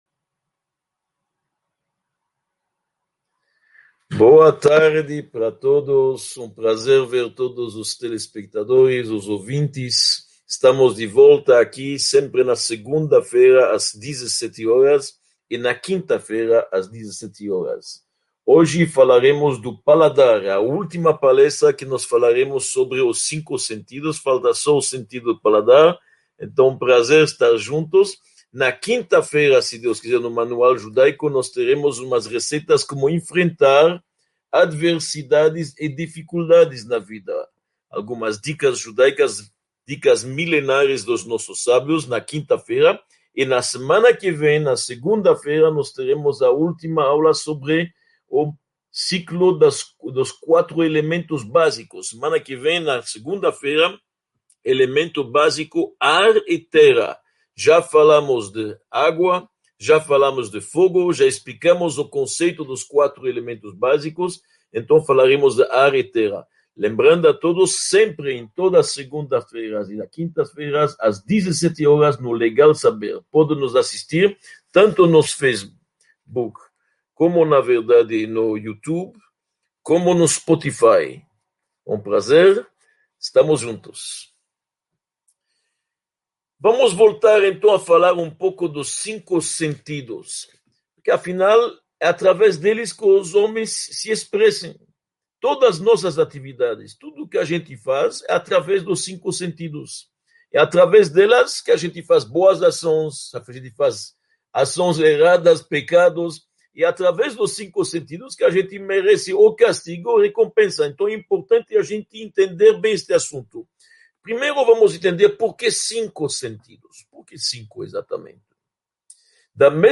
34 – Paladar: sua força e efeitos | Módulo I – Aula 34 | Manual Judaico